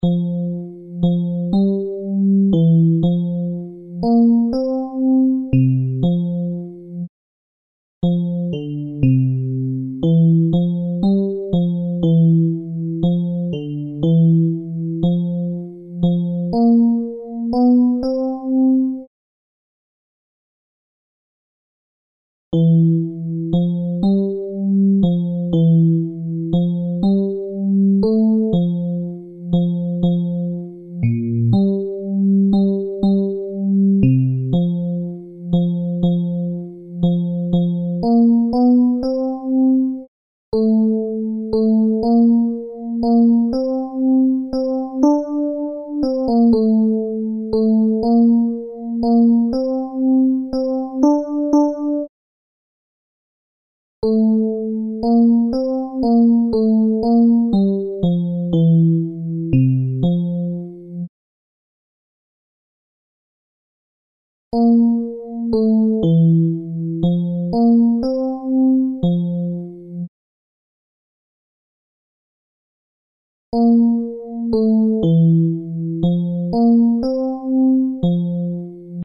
Basses
pres_du_tendre_basses.MP3